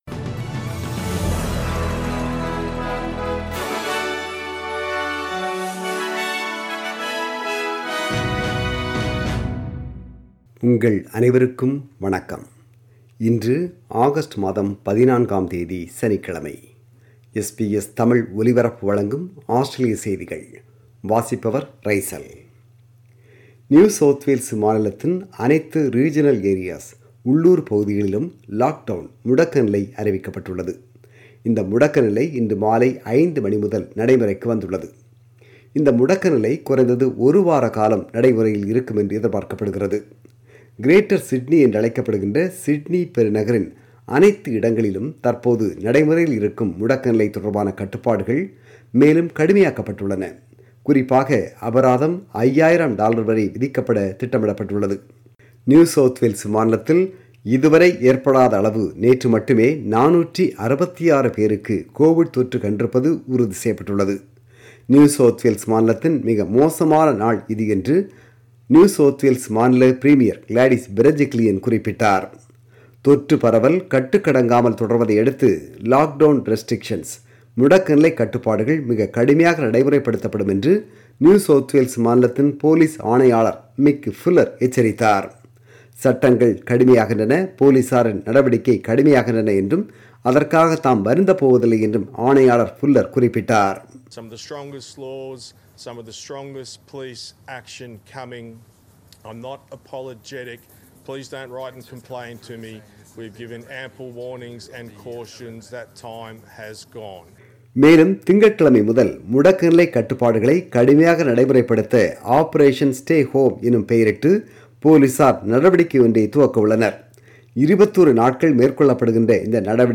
ஆஸ்திரேலிய செய்திகள்: 14 ஜூலை 2021 சனிக்கிழமை வாசித்தவர்